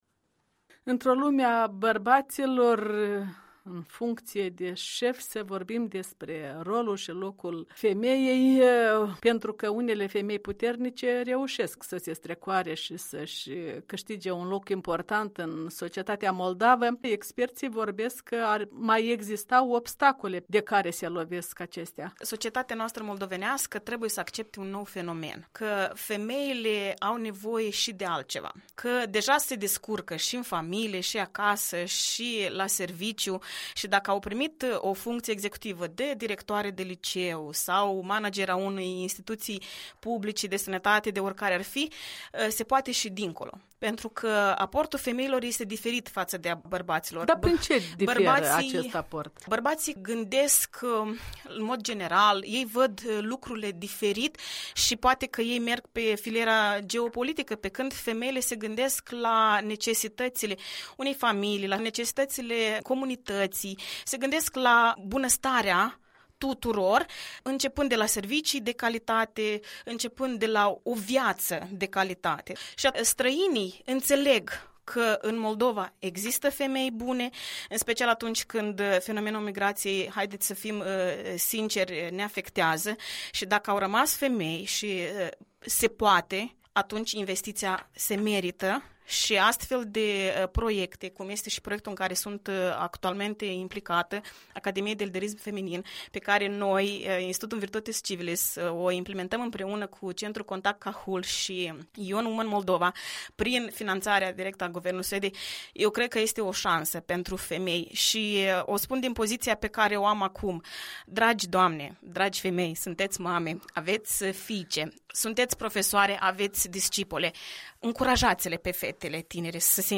Despre egalitatea femeii și a bărbatului în viața socială și politică, o discuție